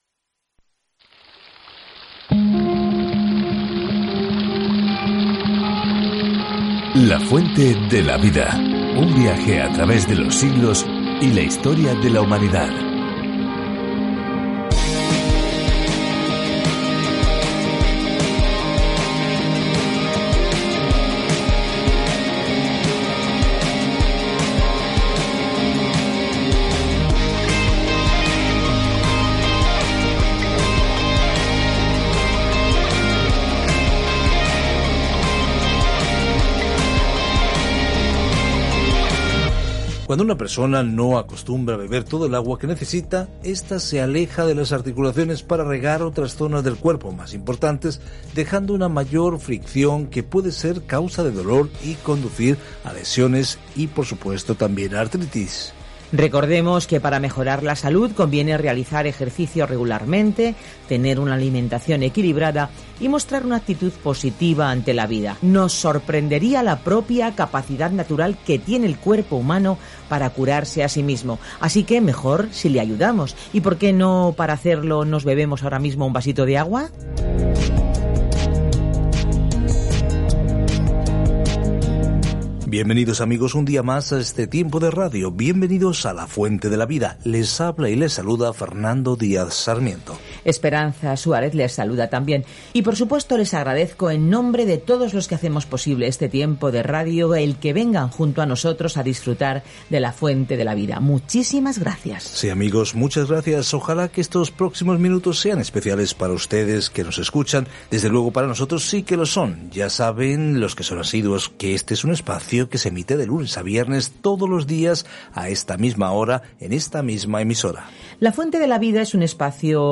Scripture Daniel 11:15-36 Day 27 Start this Plan Day 29 About this Plan El libro de Daniel es a la vez una biografía de un hombre que creyó en Dios y una visión profética de quién eventualmente gobernará el mundo. Viaja diariamente a través de Daniel mientras escuchas el estudio de audio y lees versículos seleccionados de la palabra de Dios.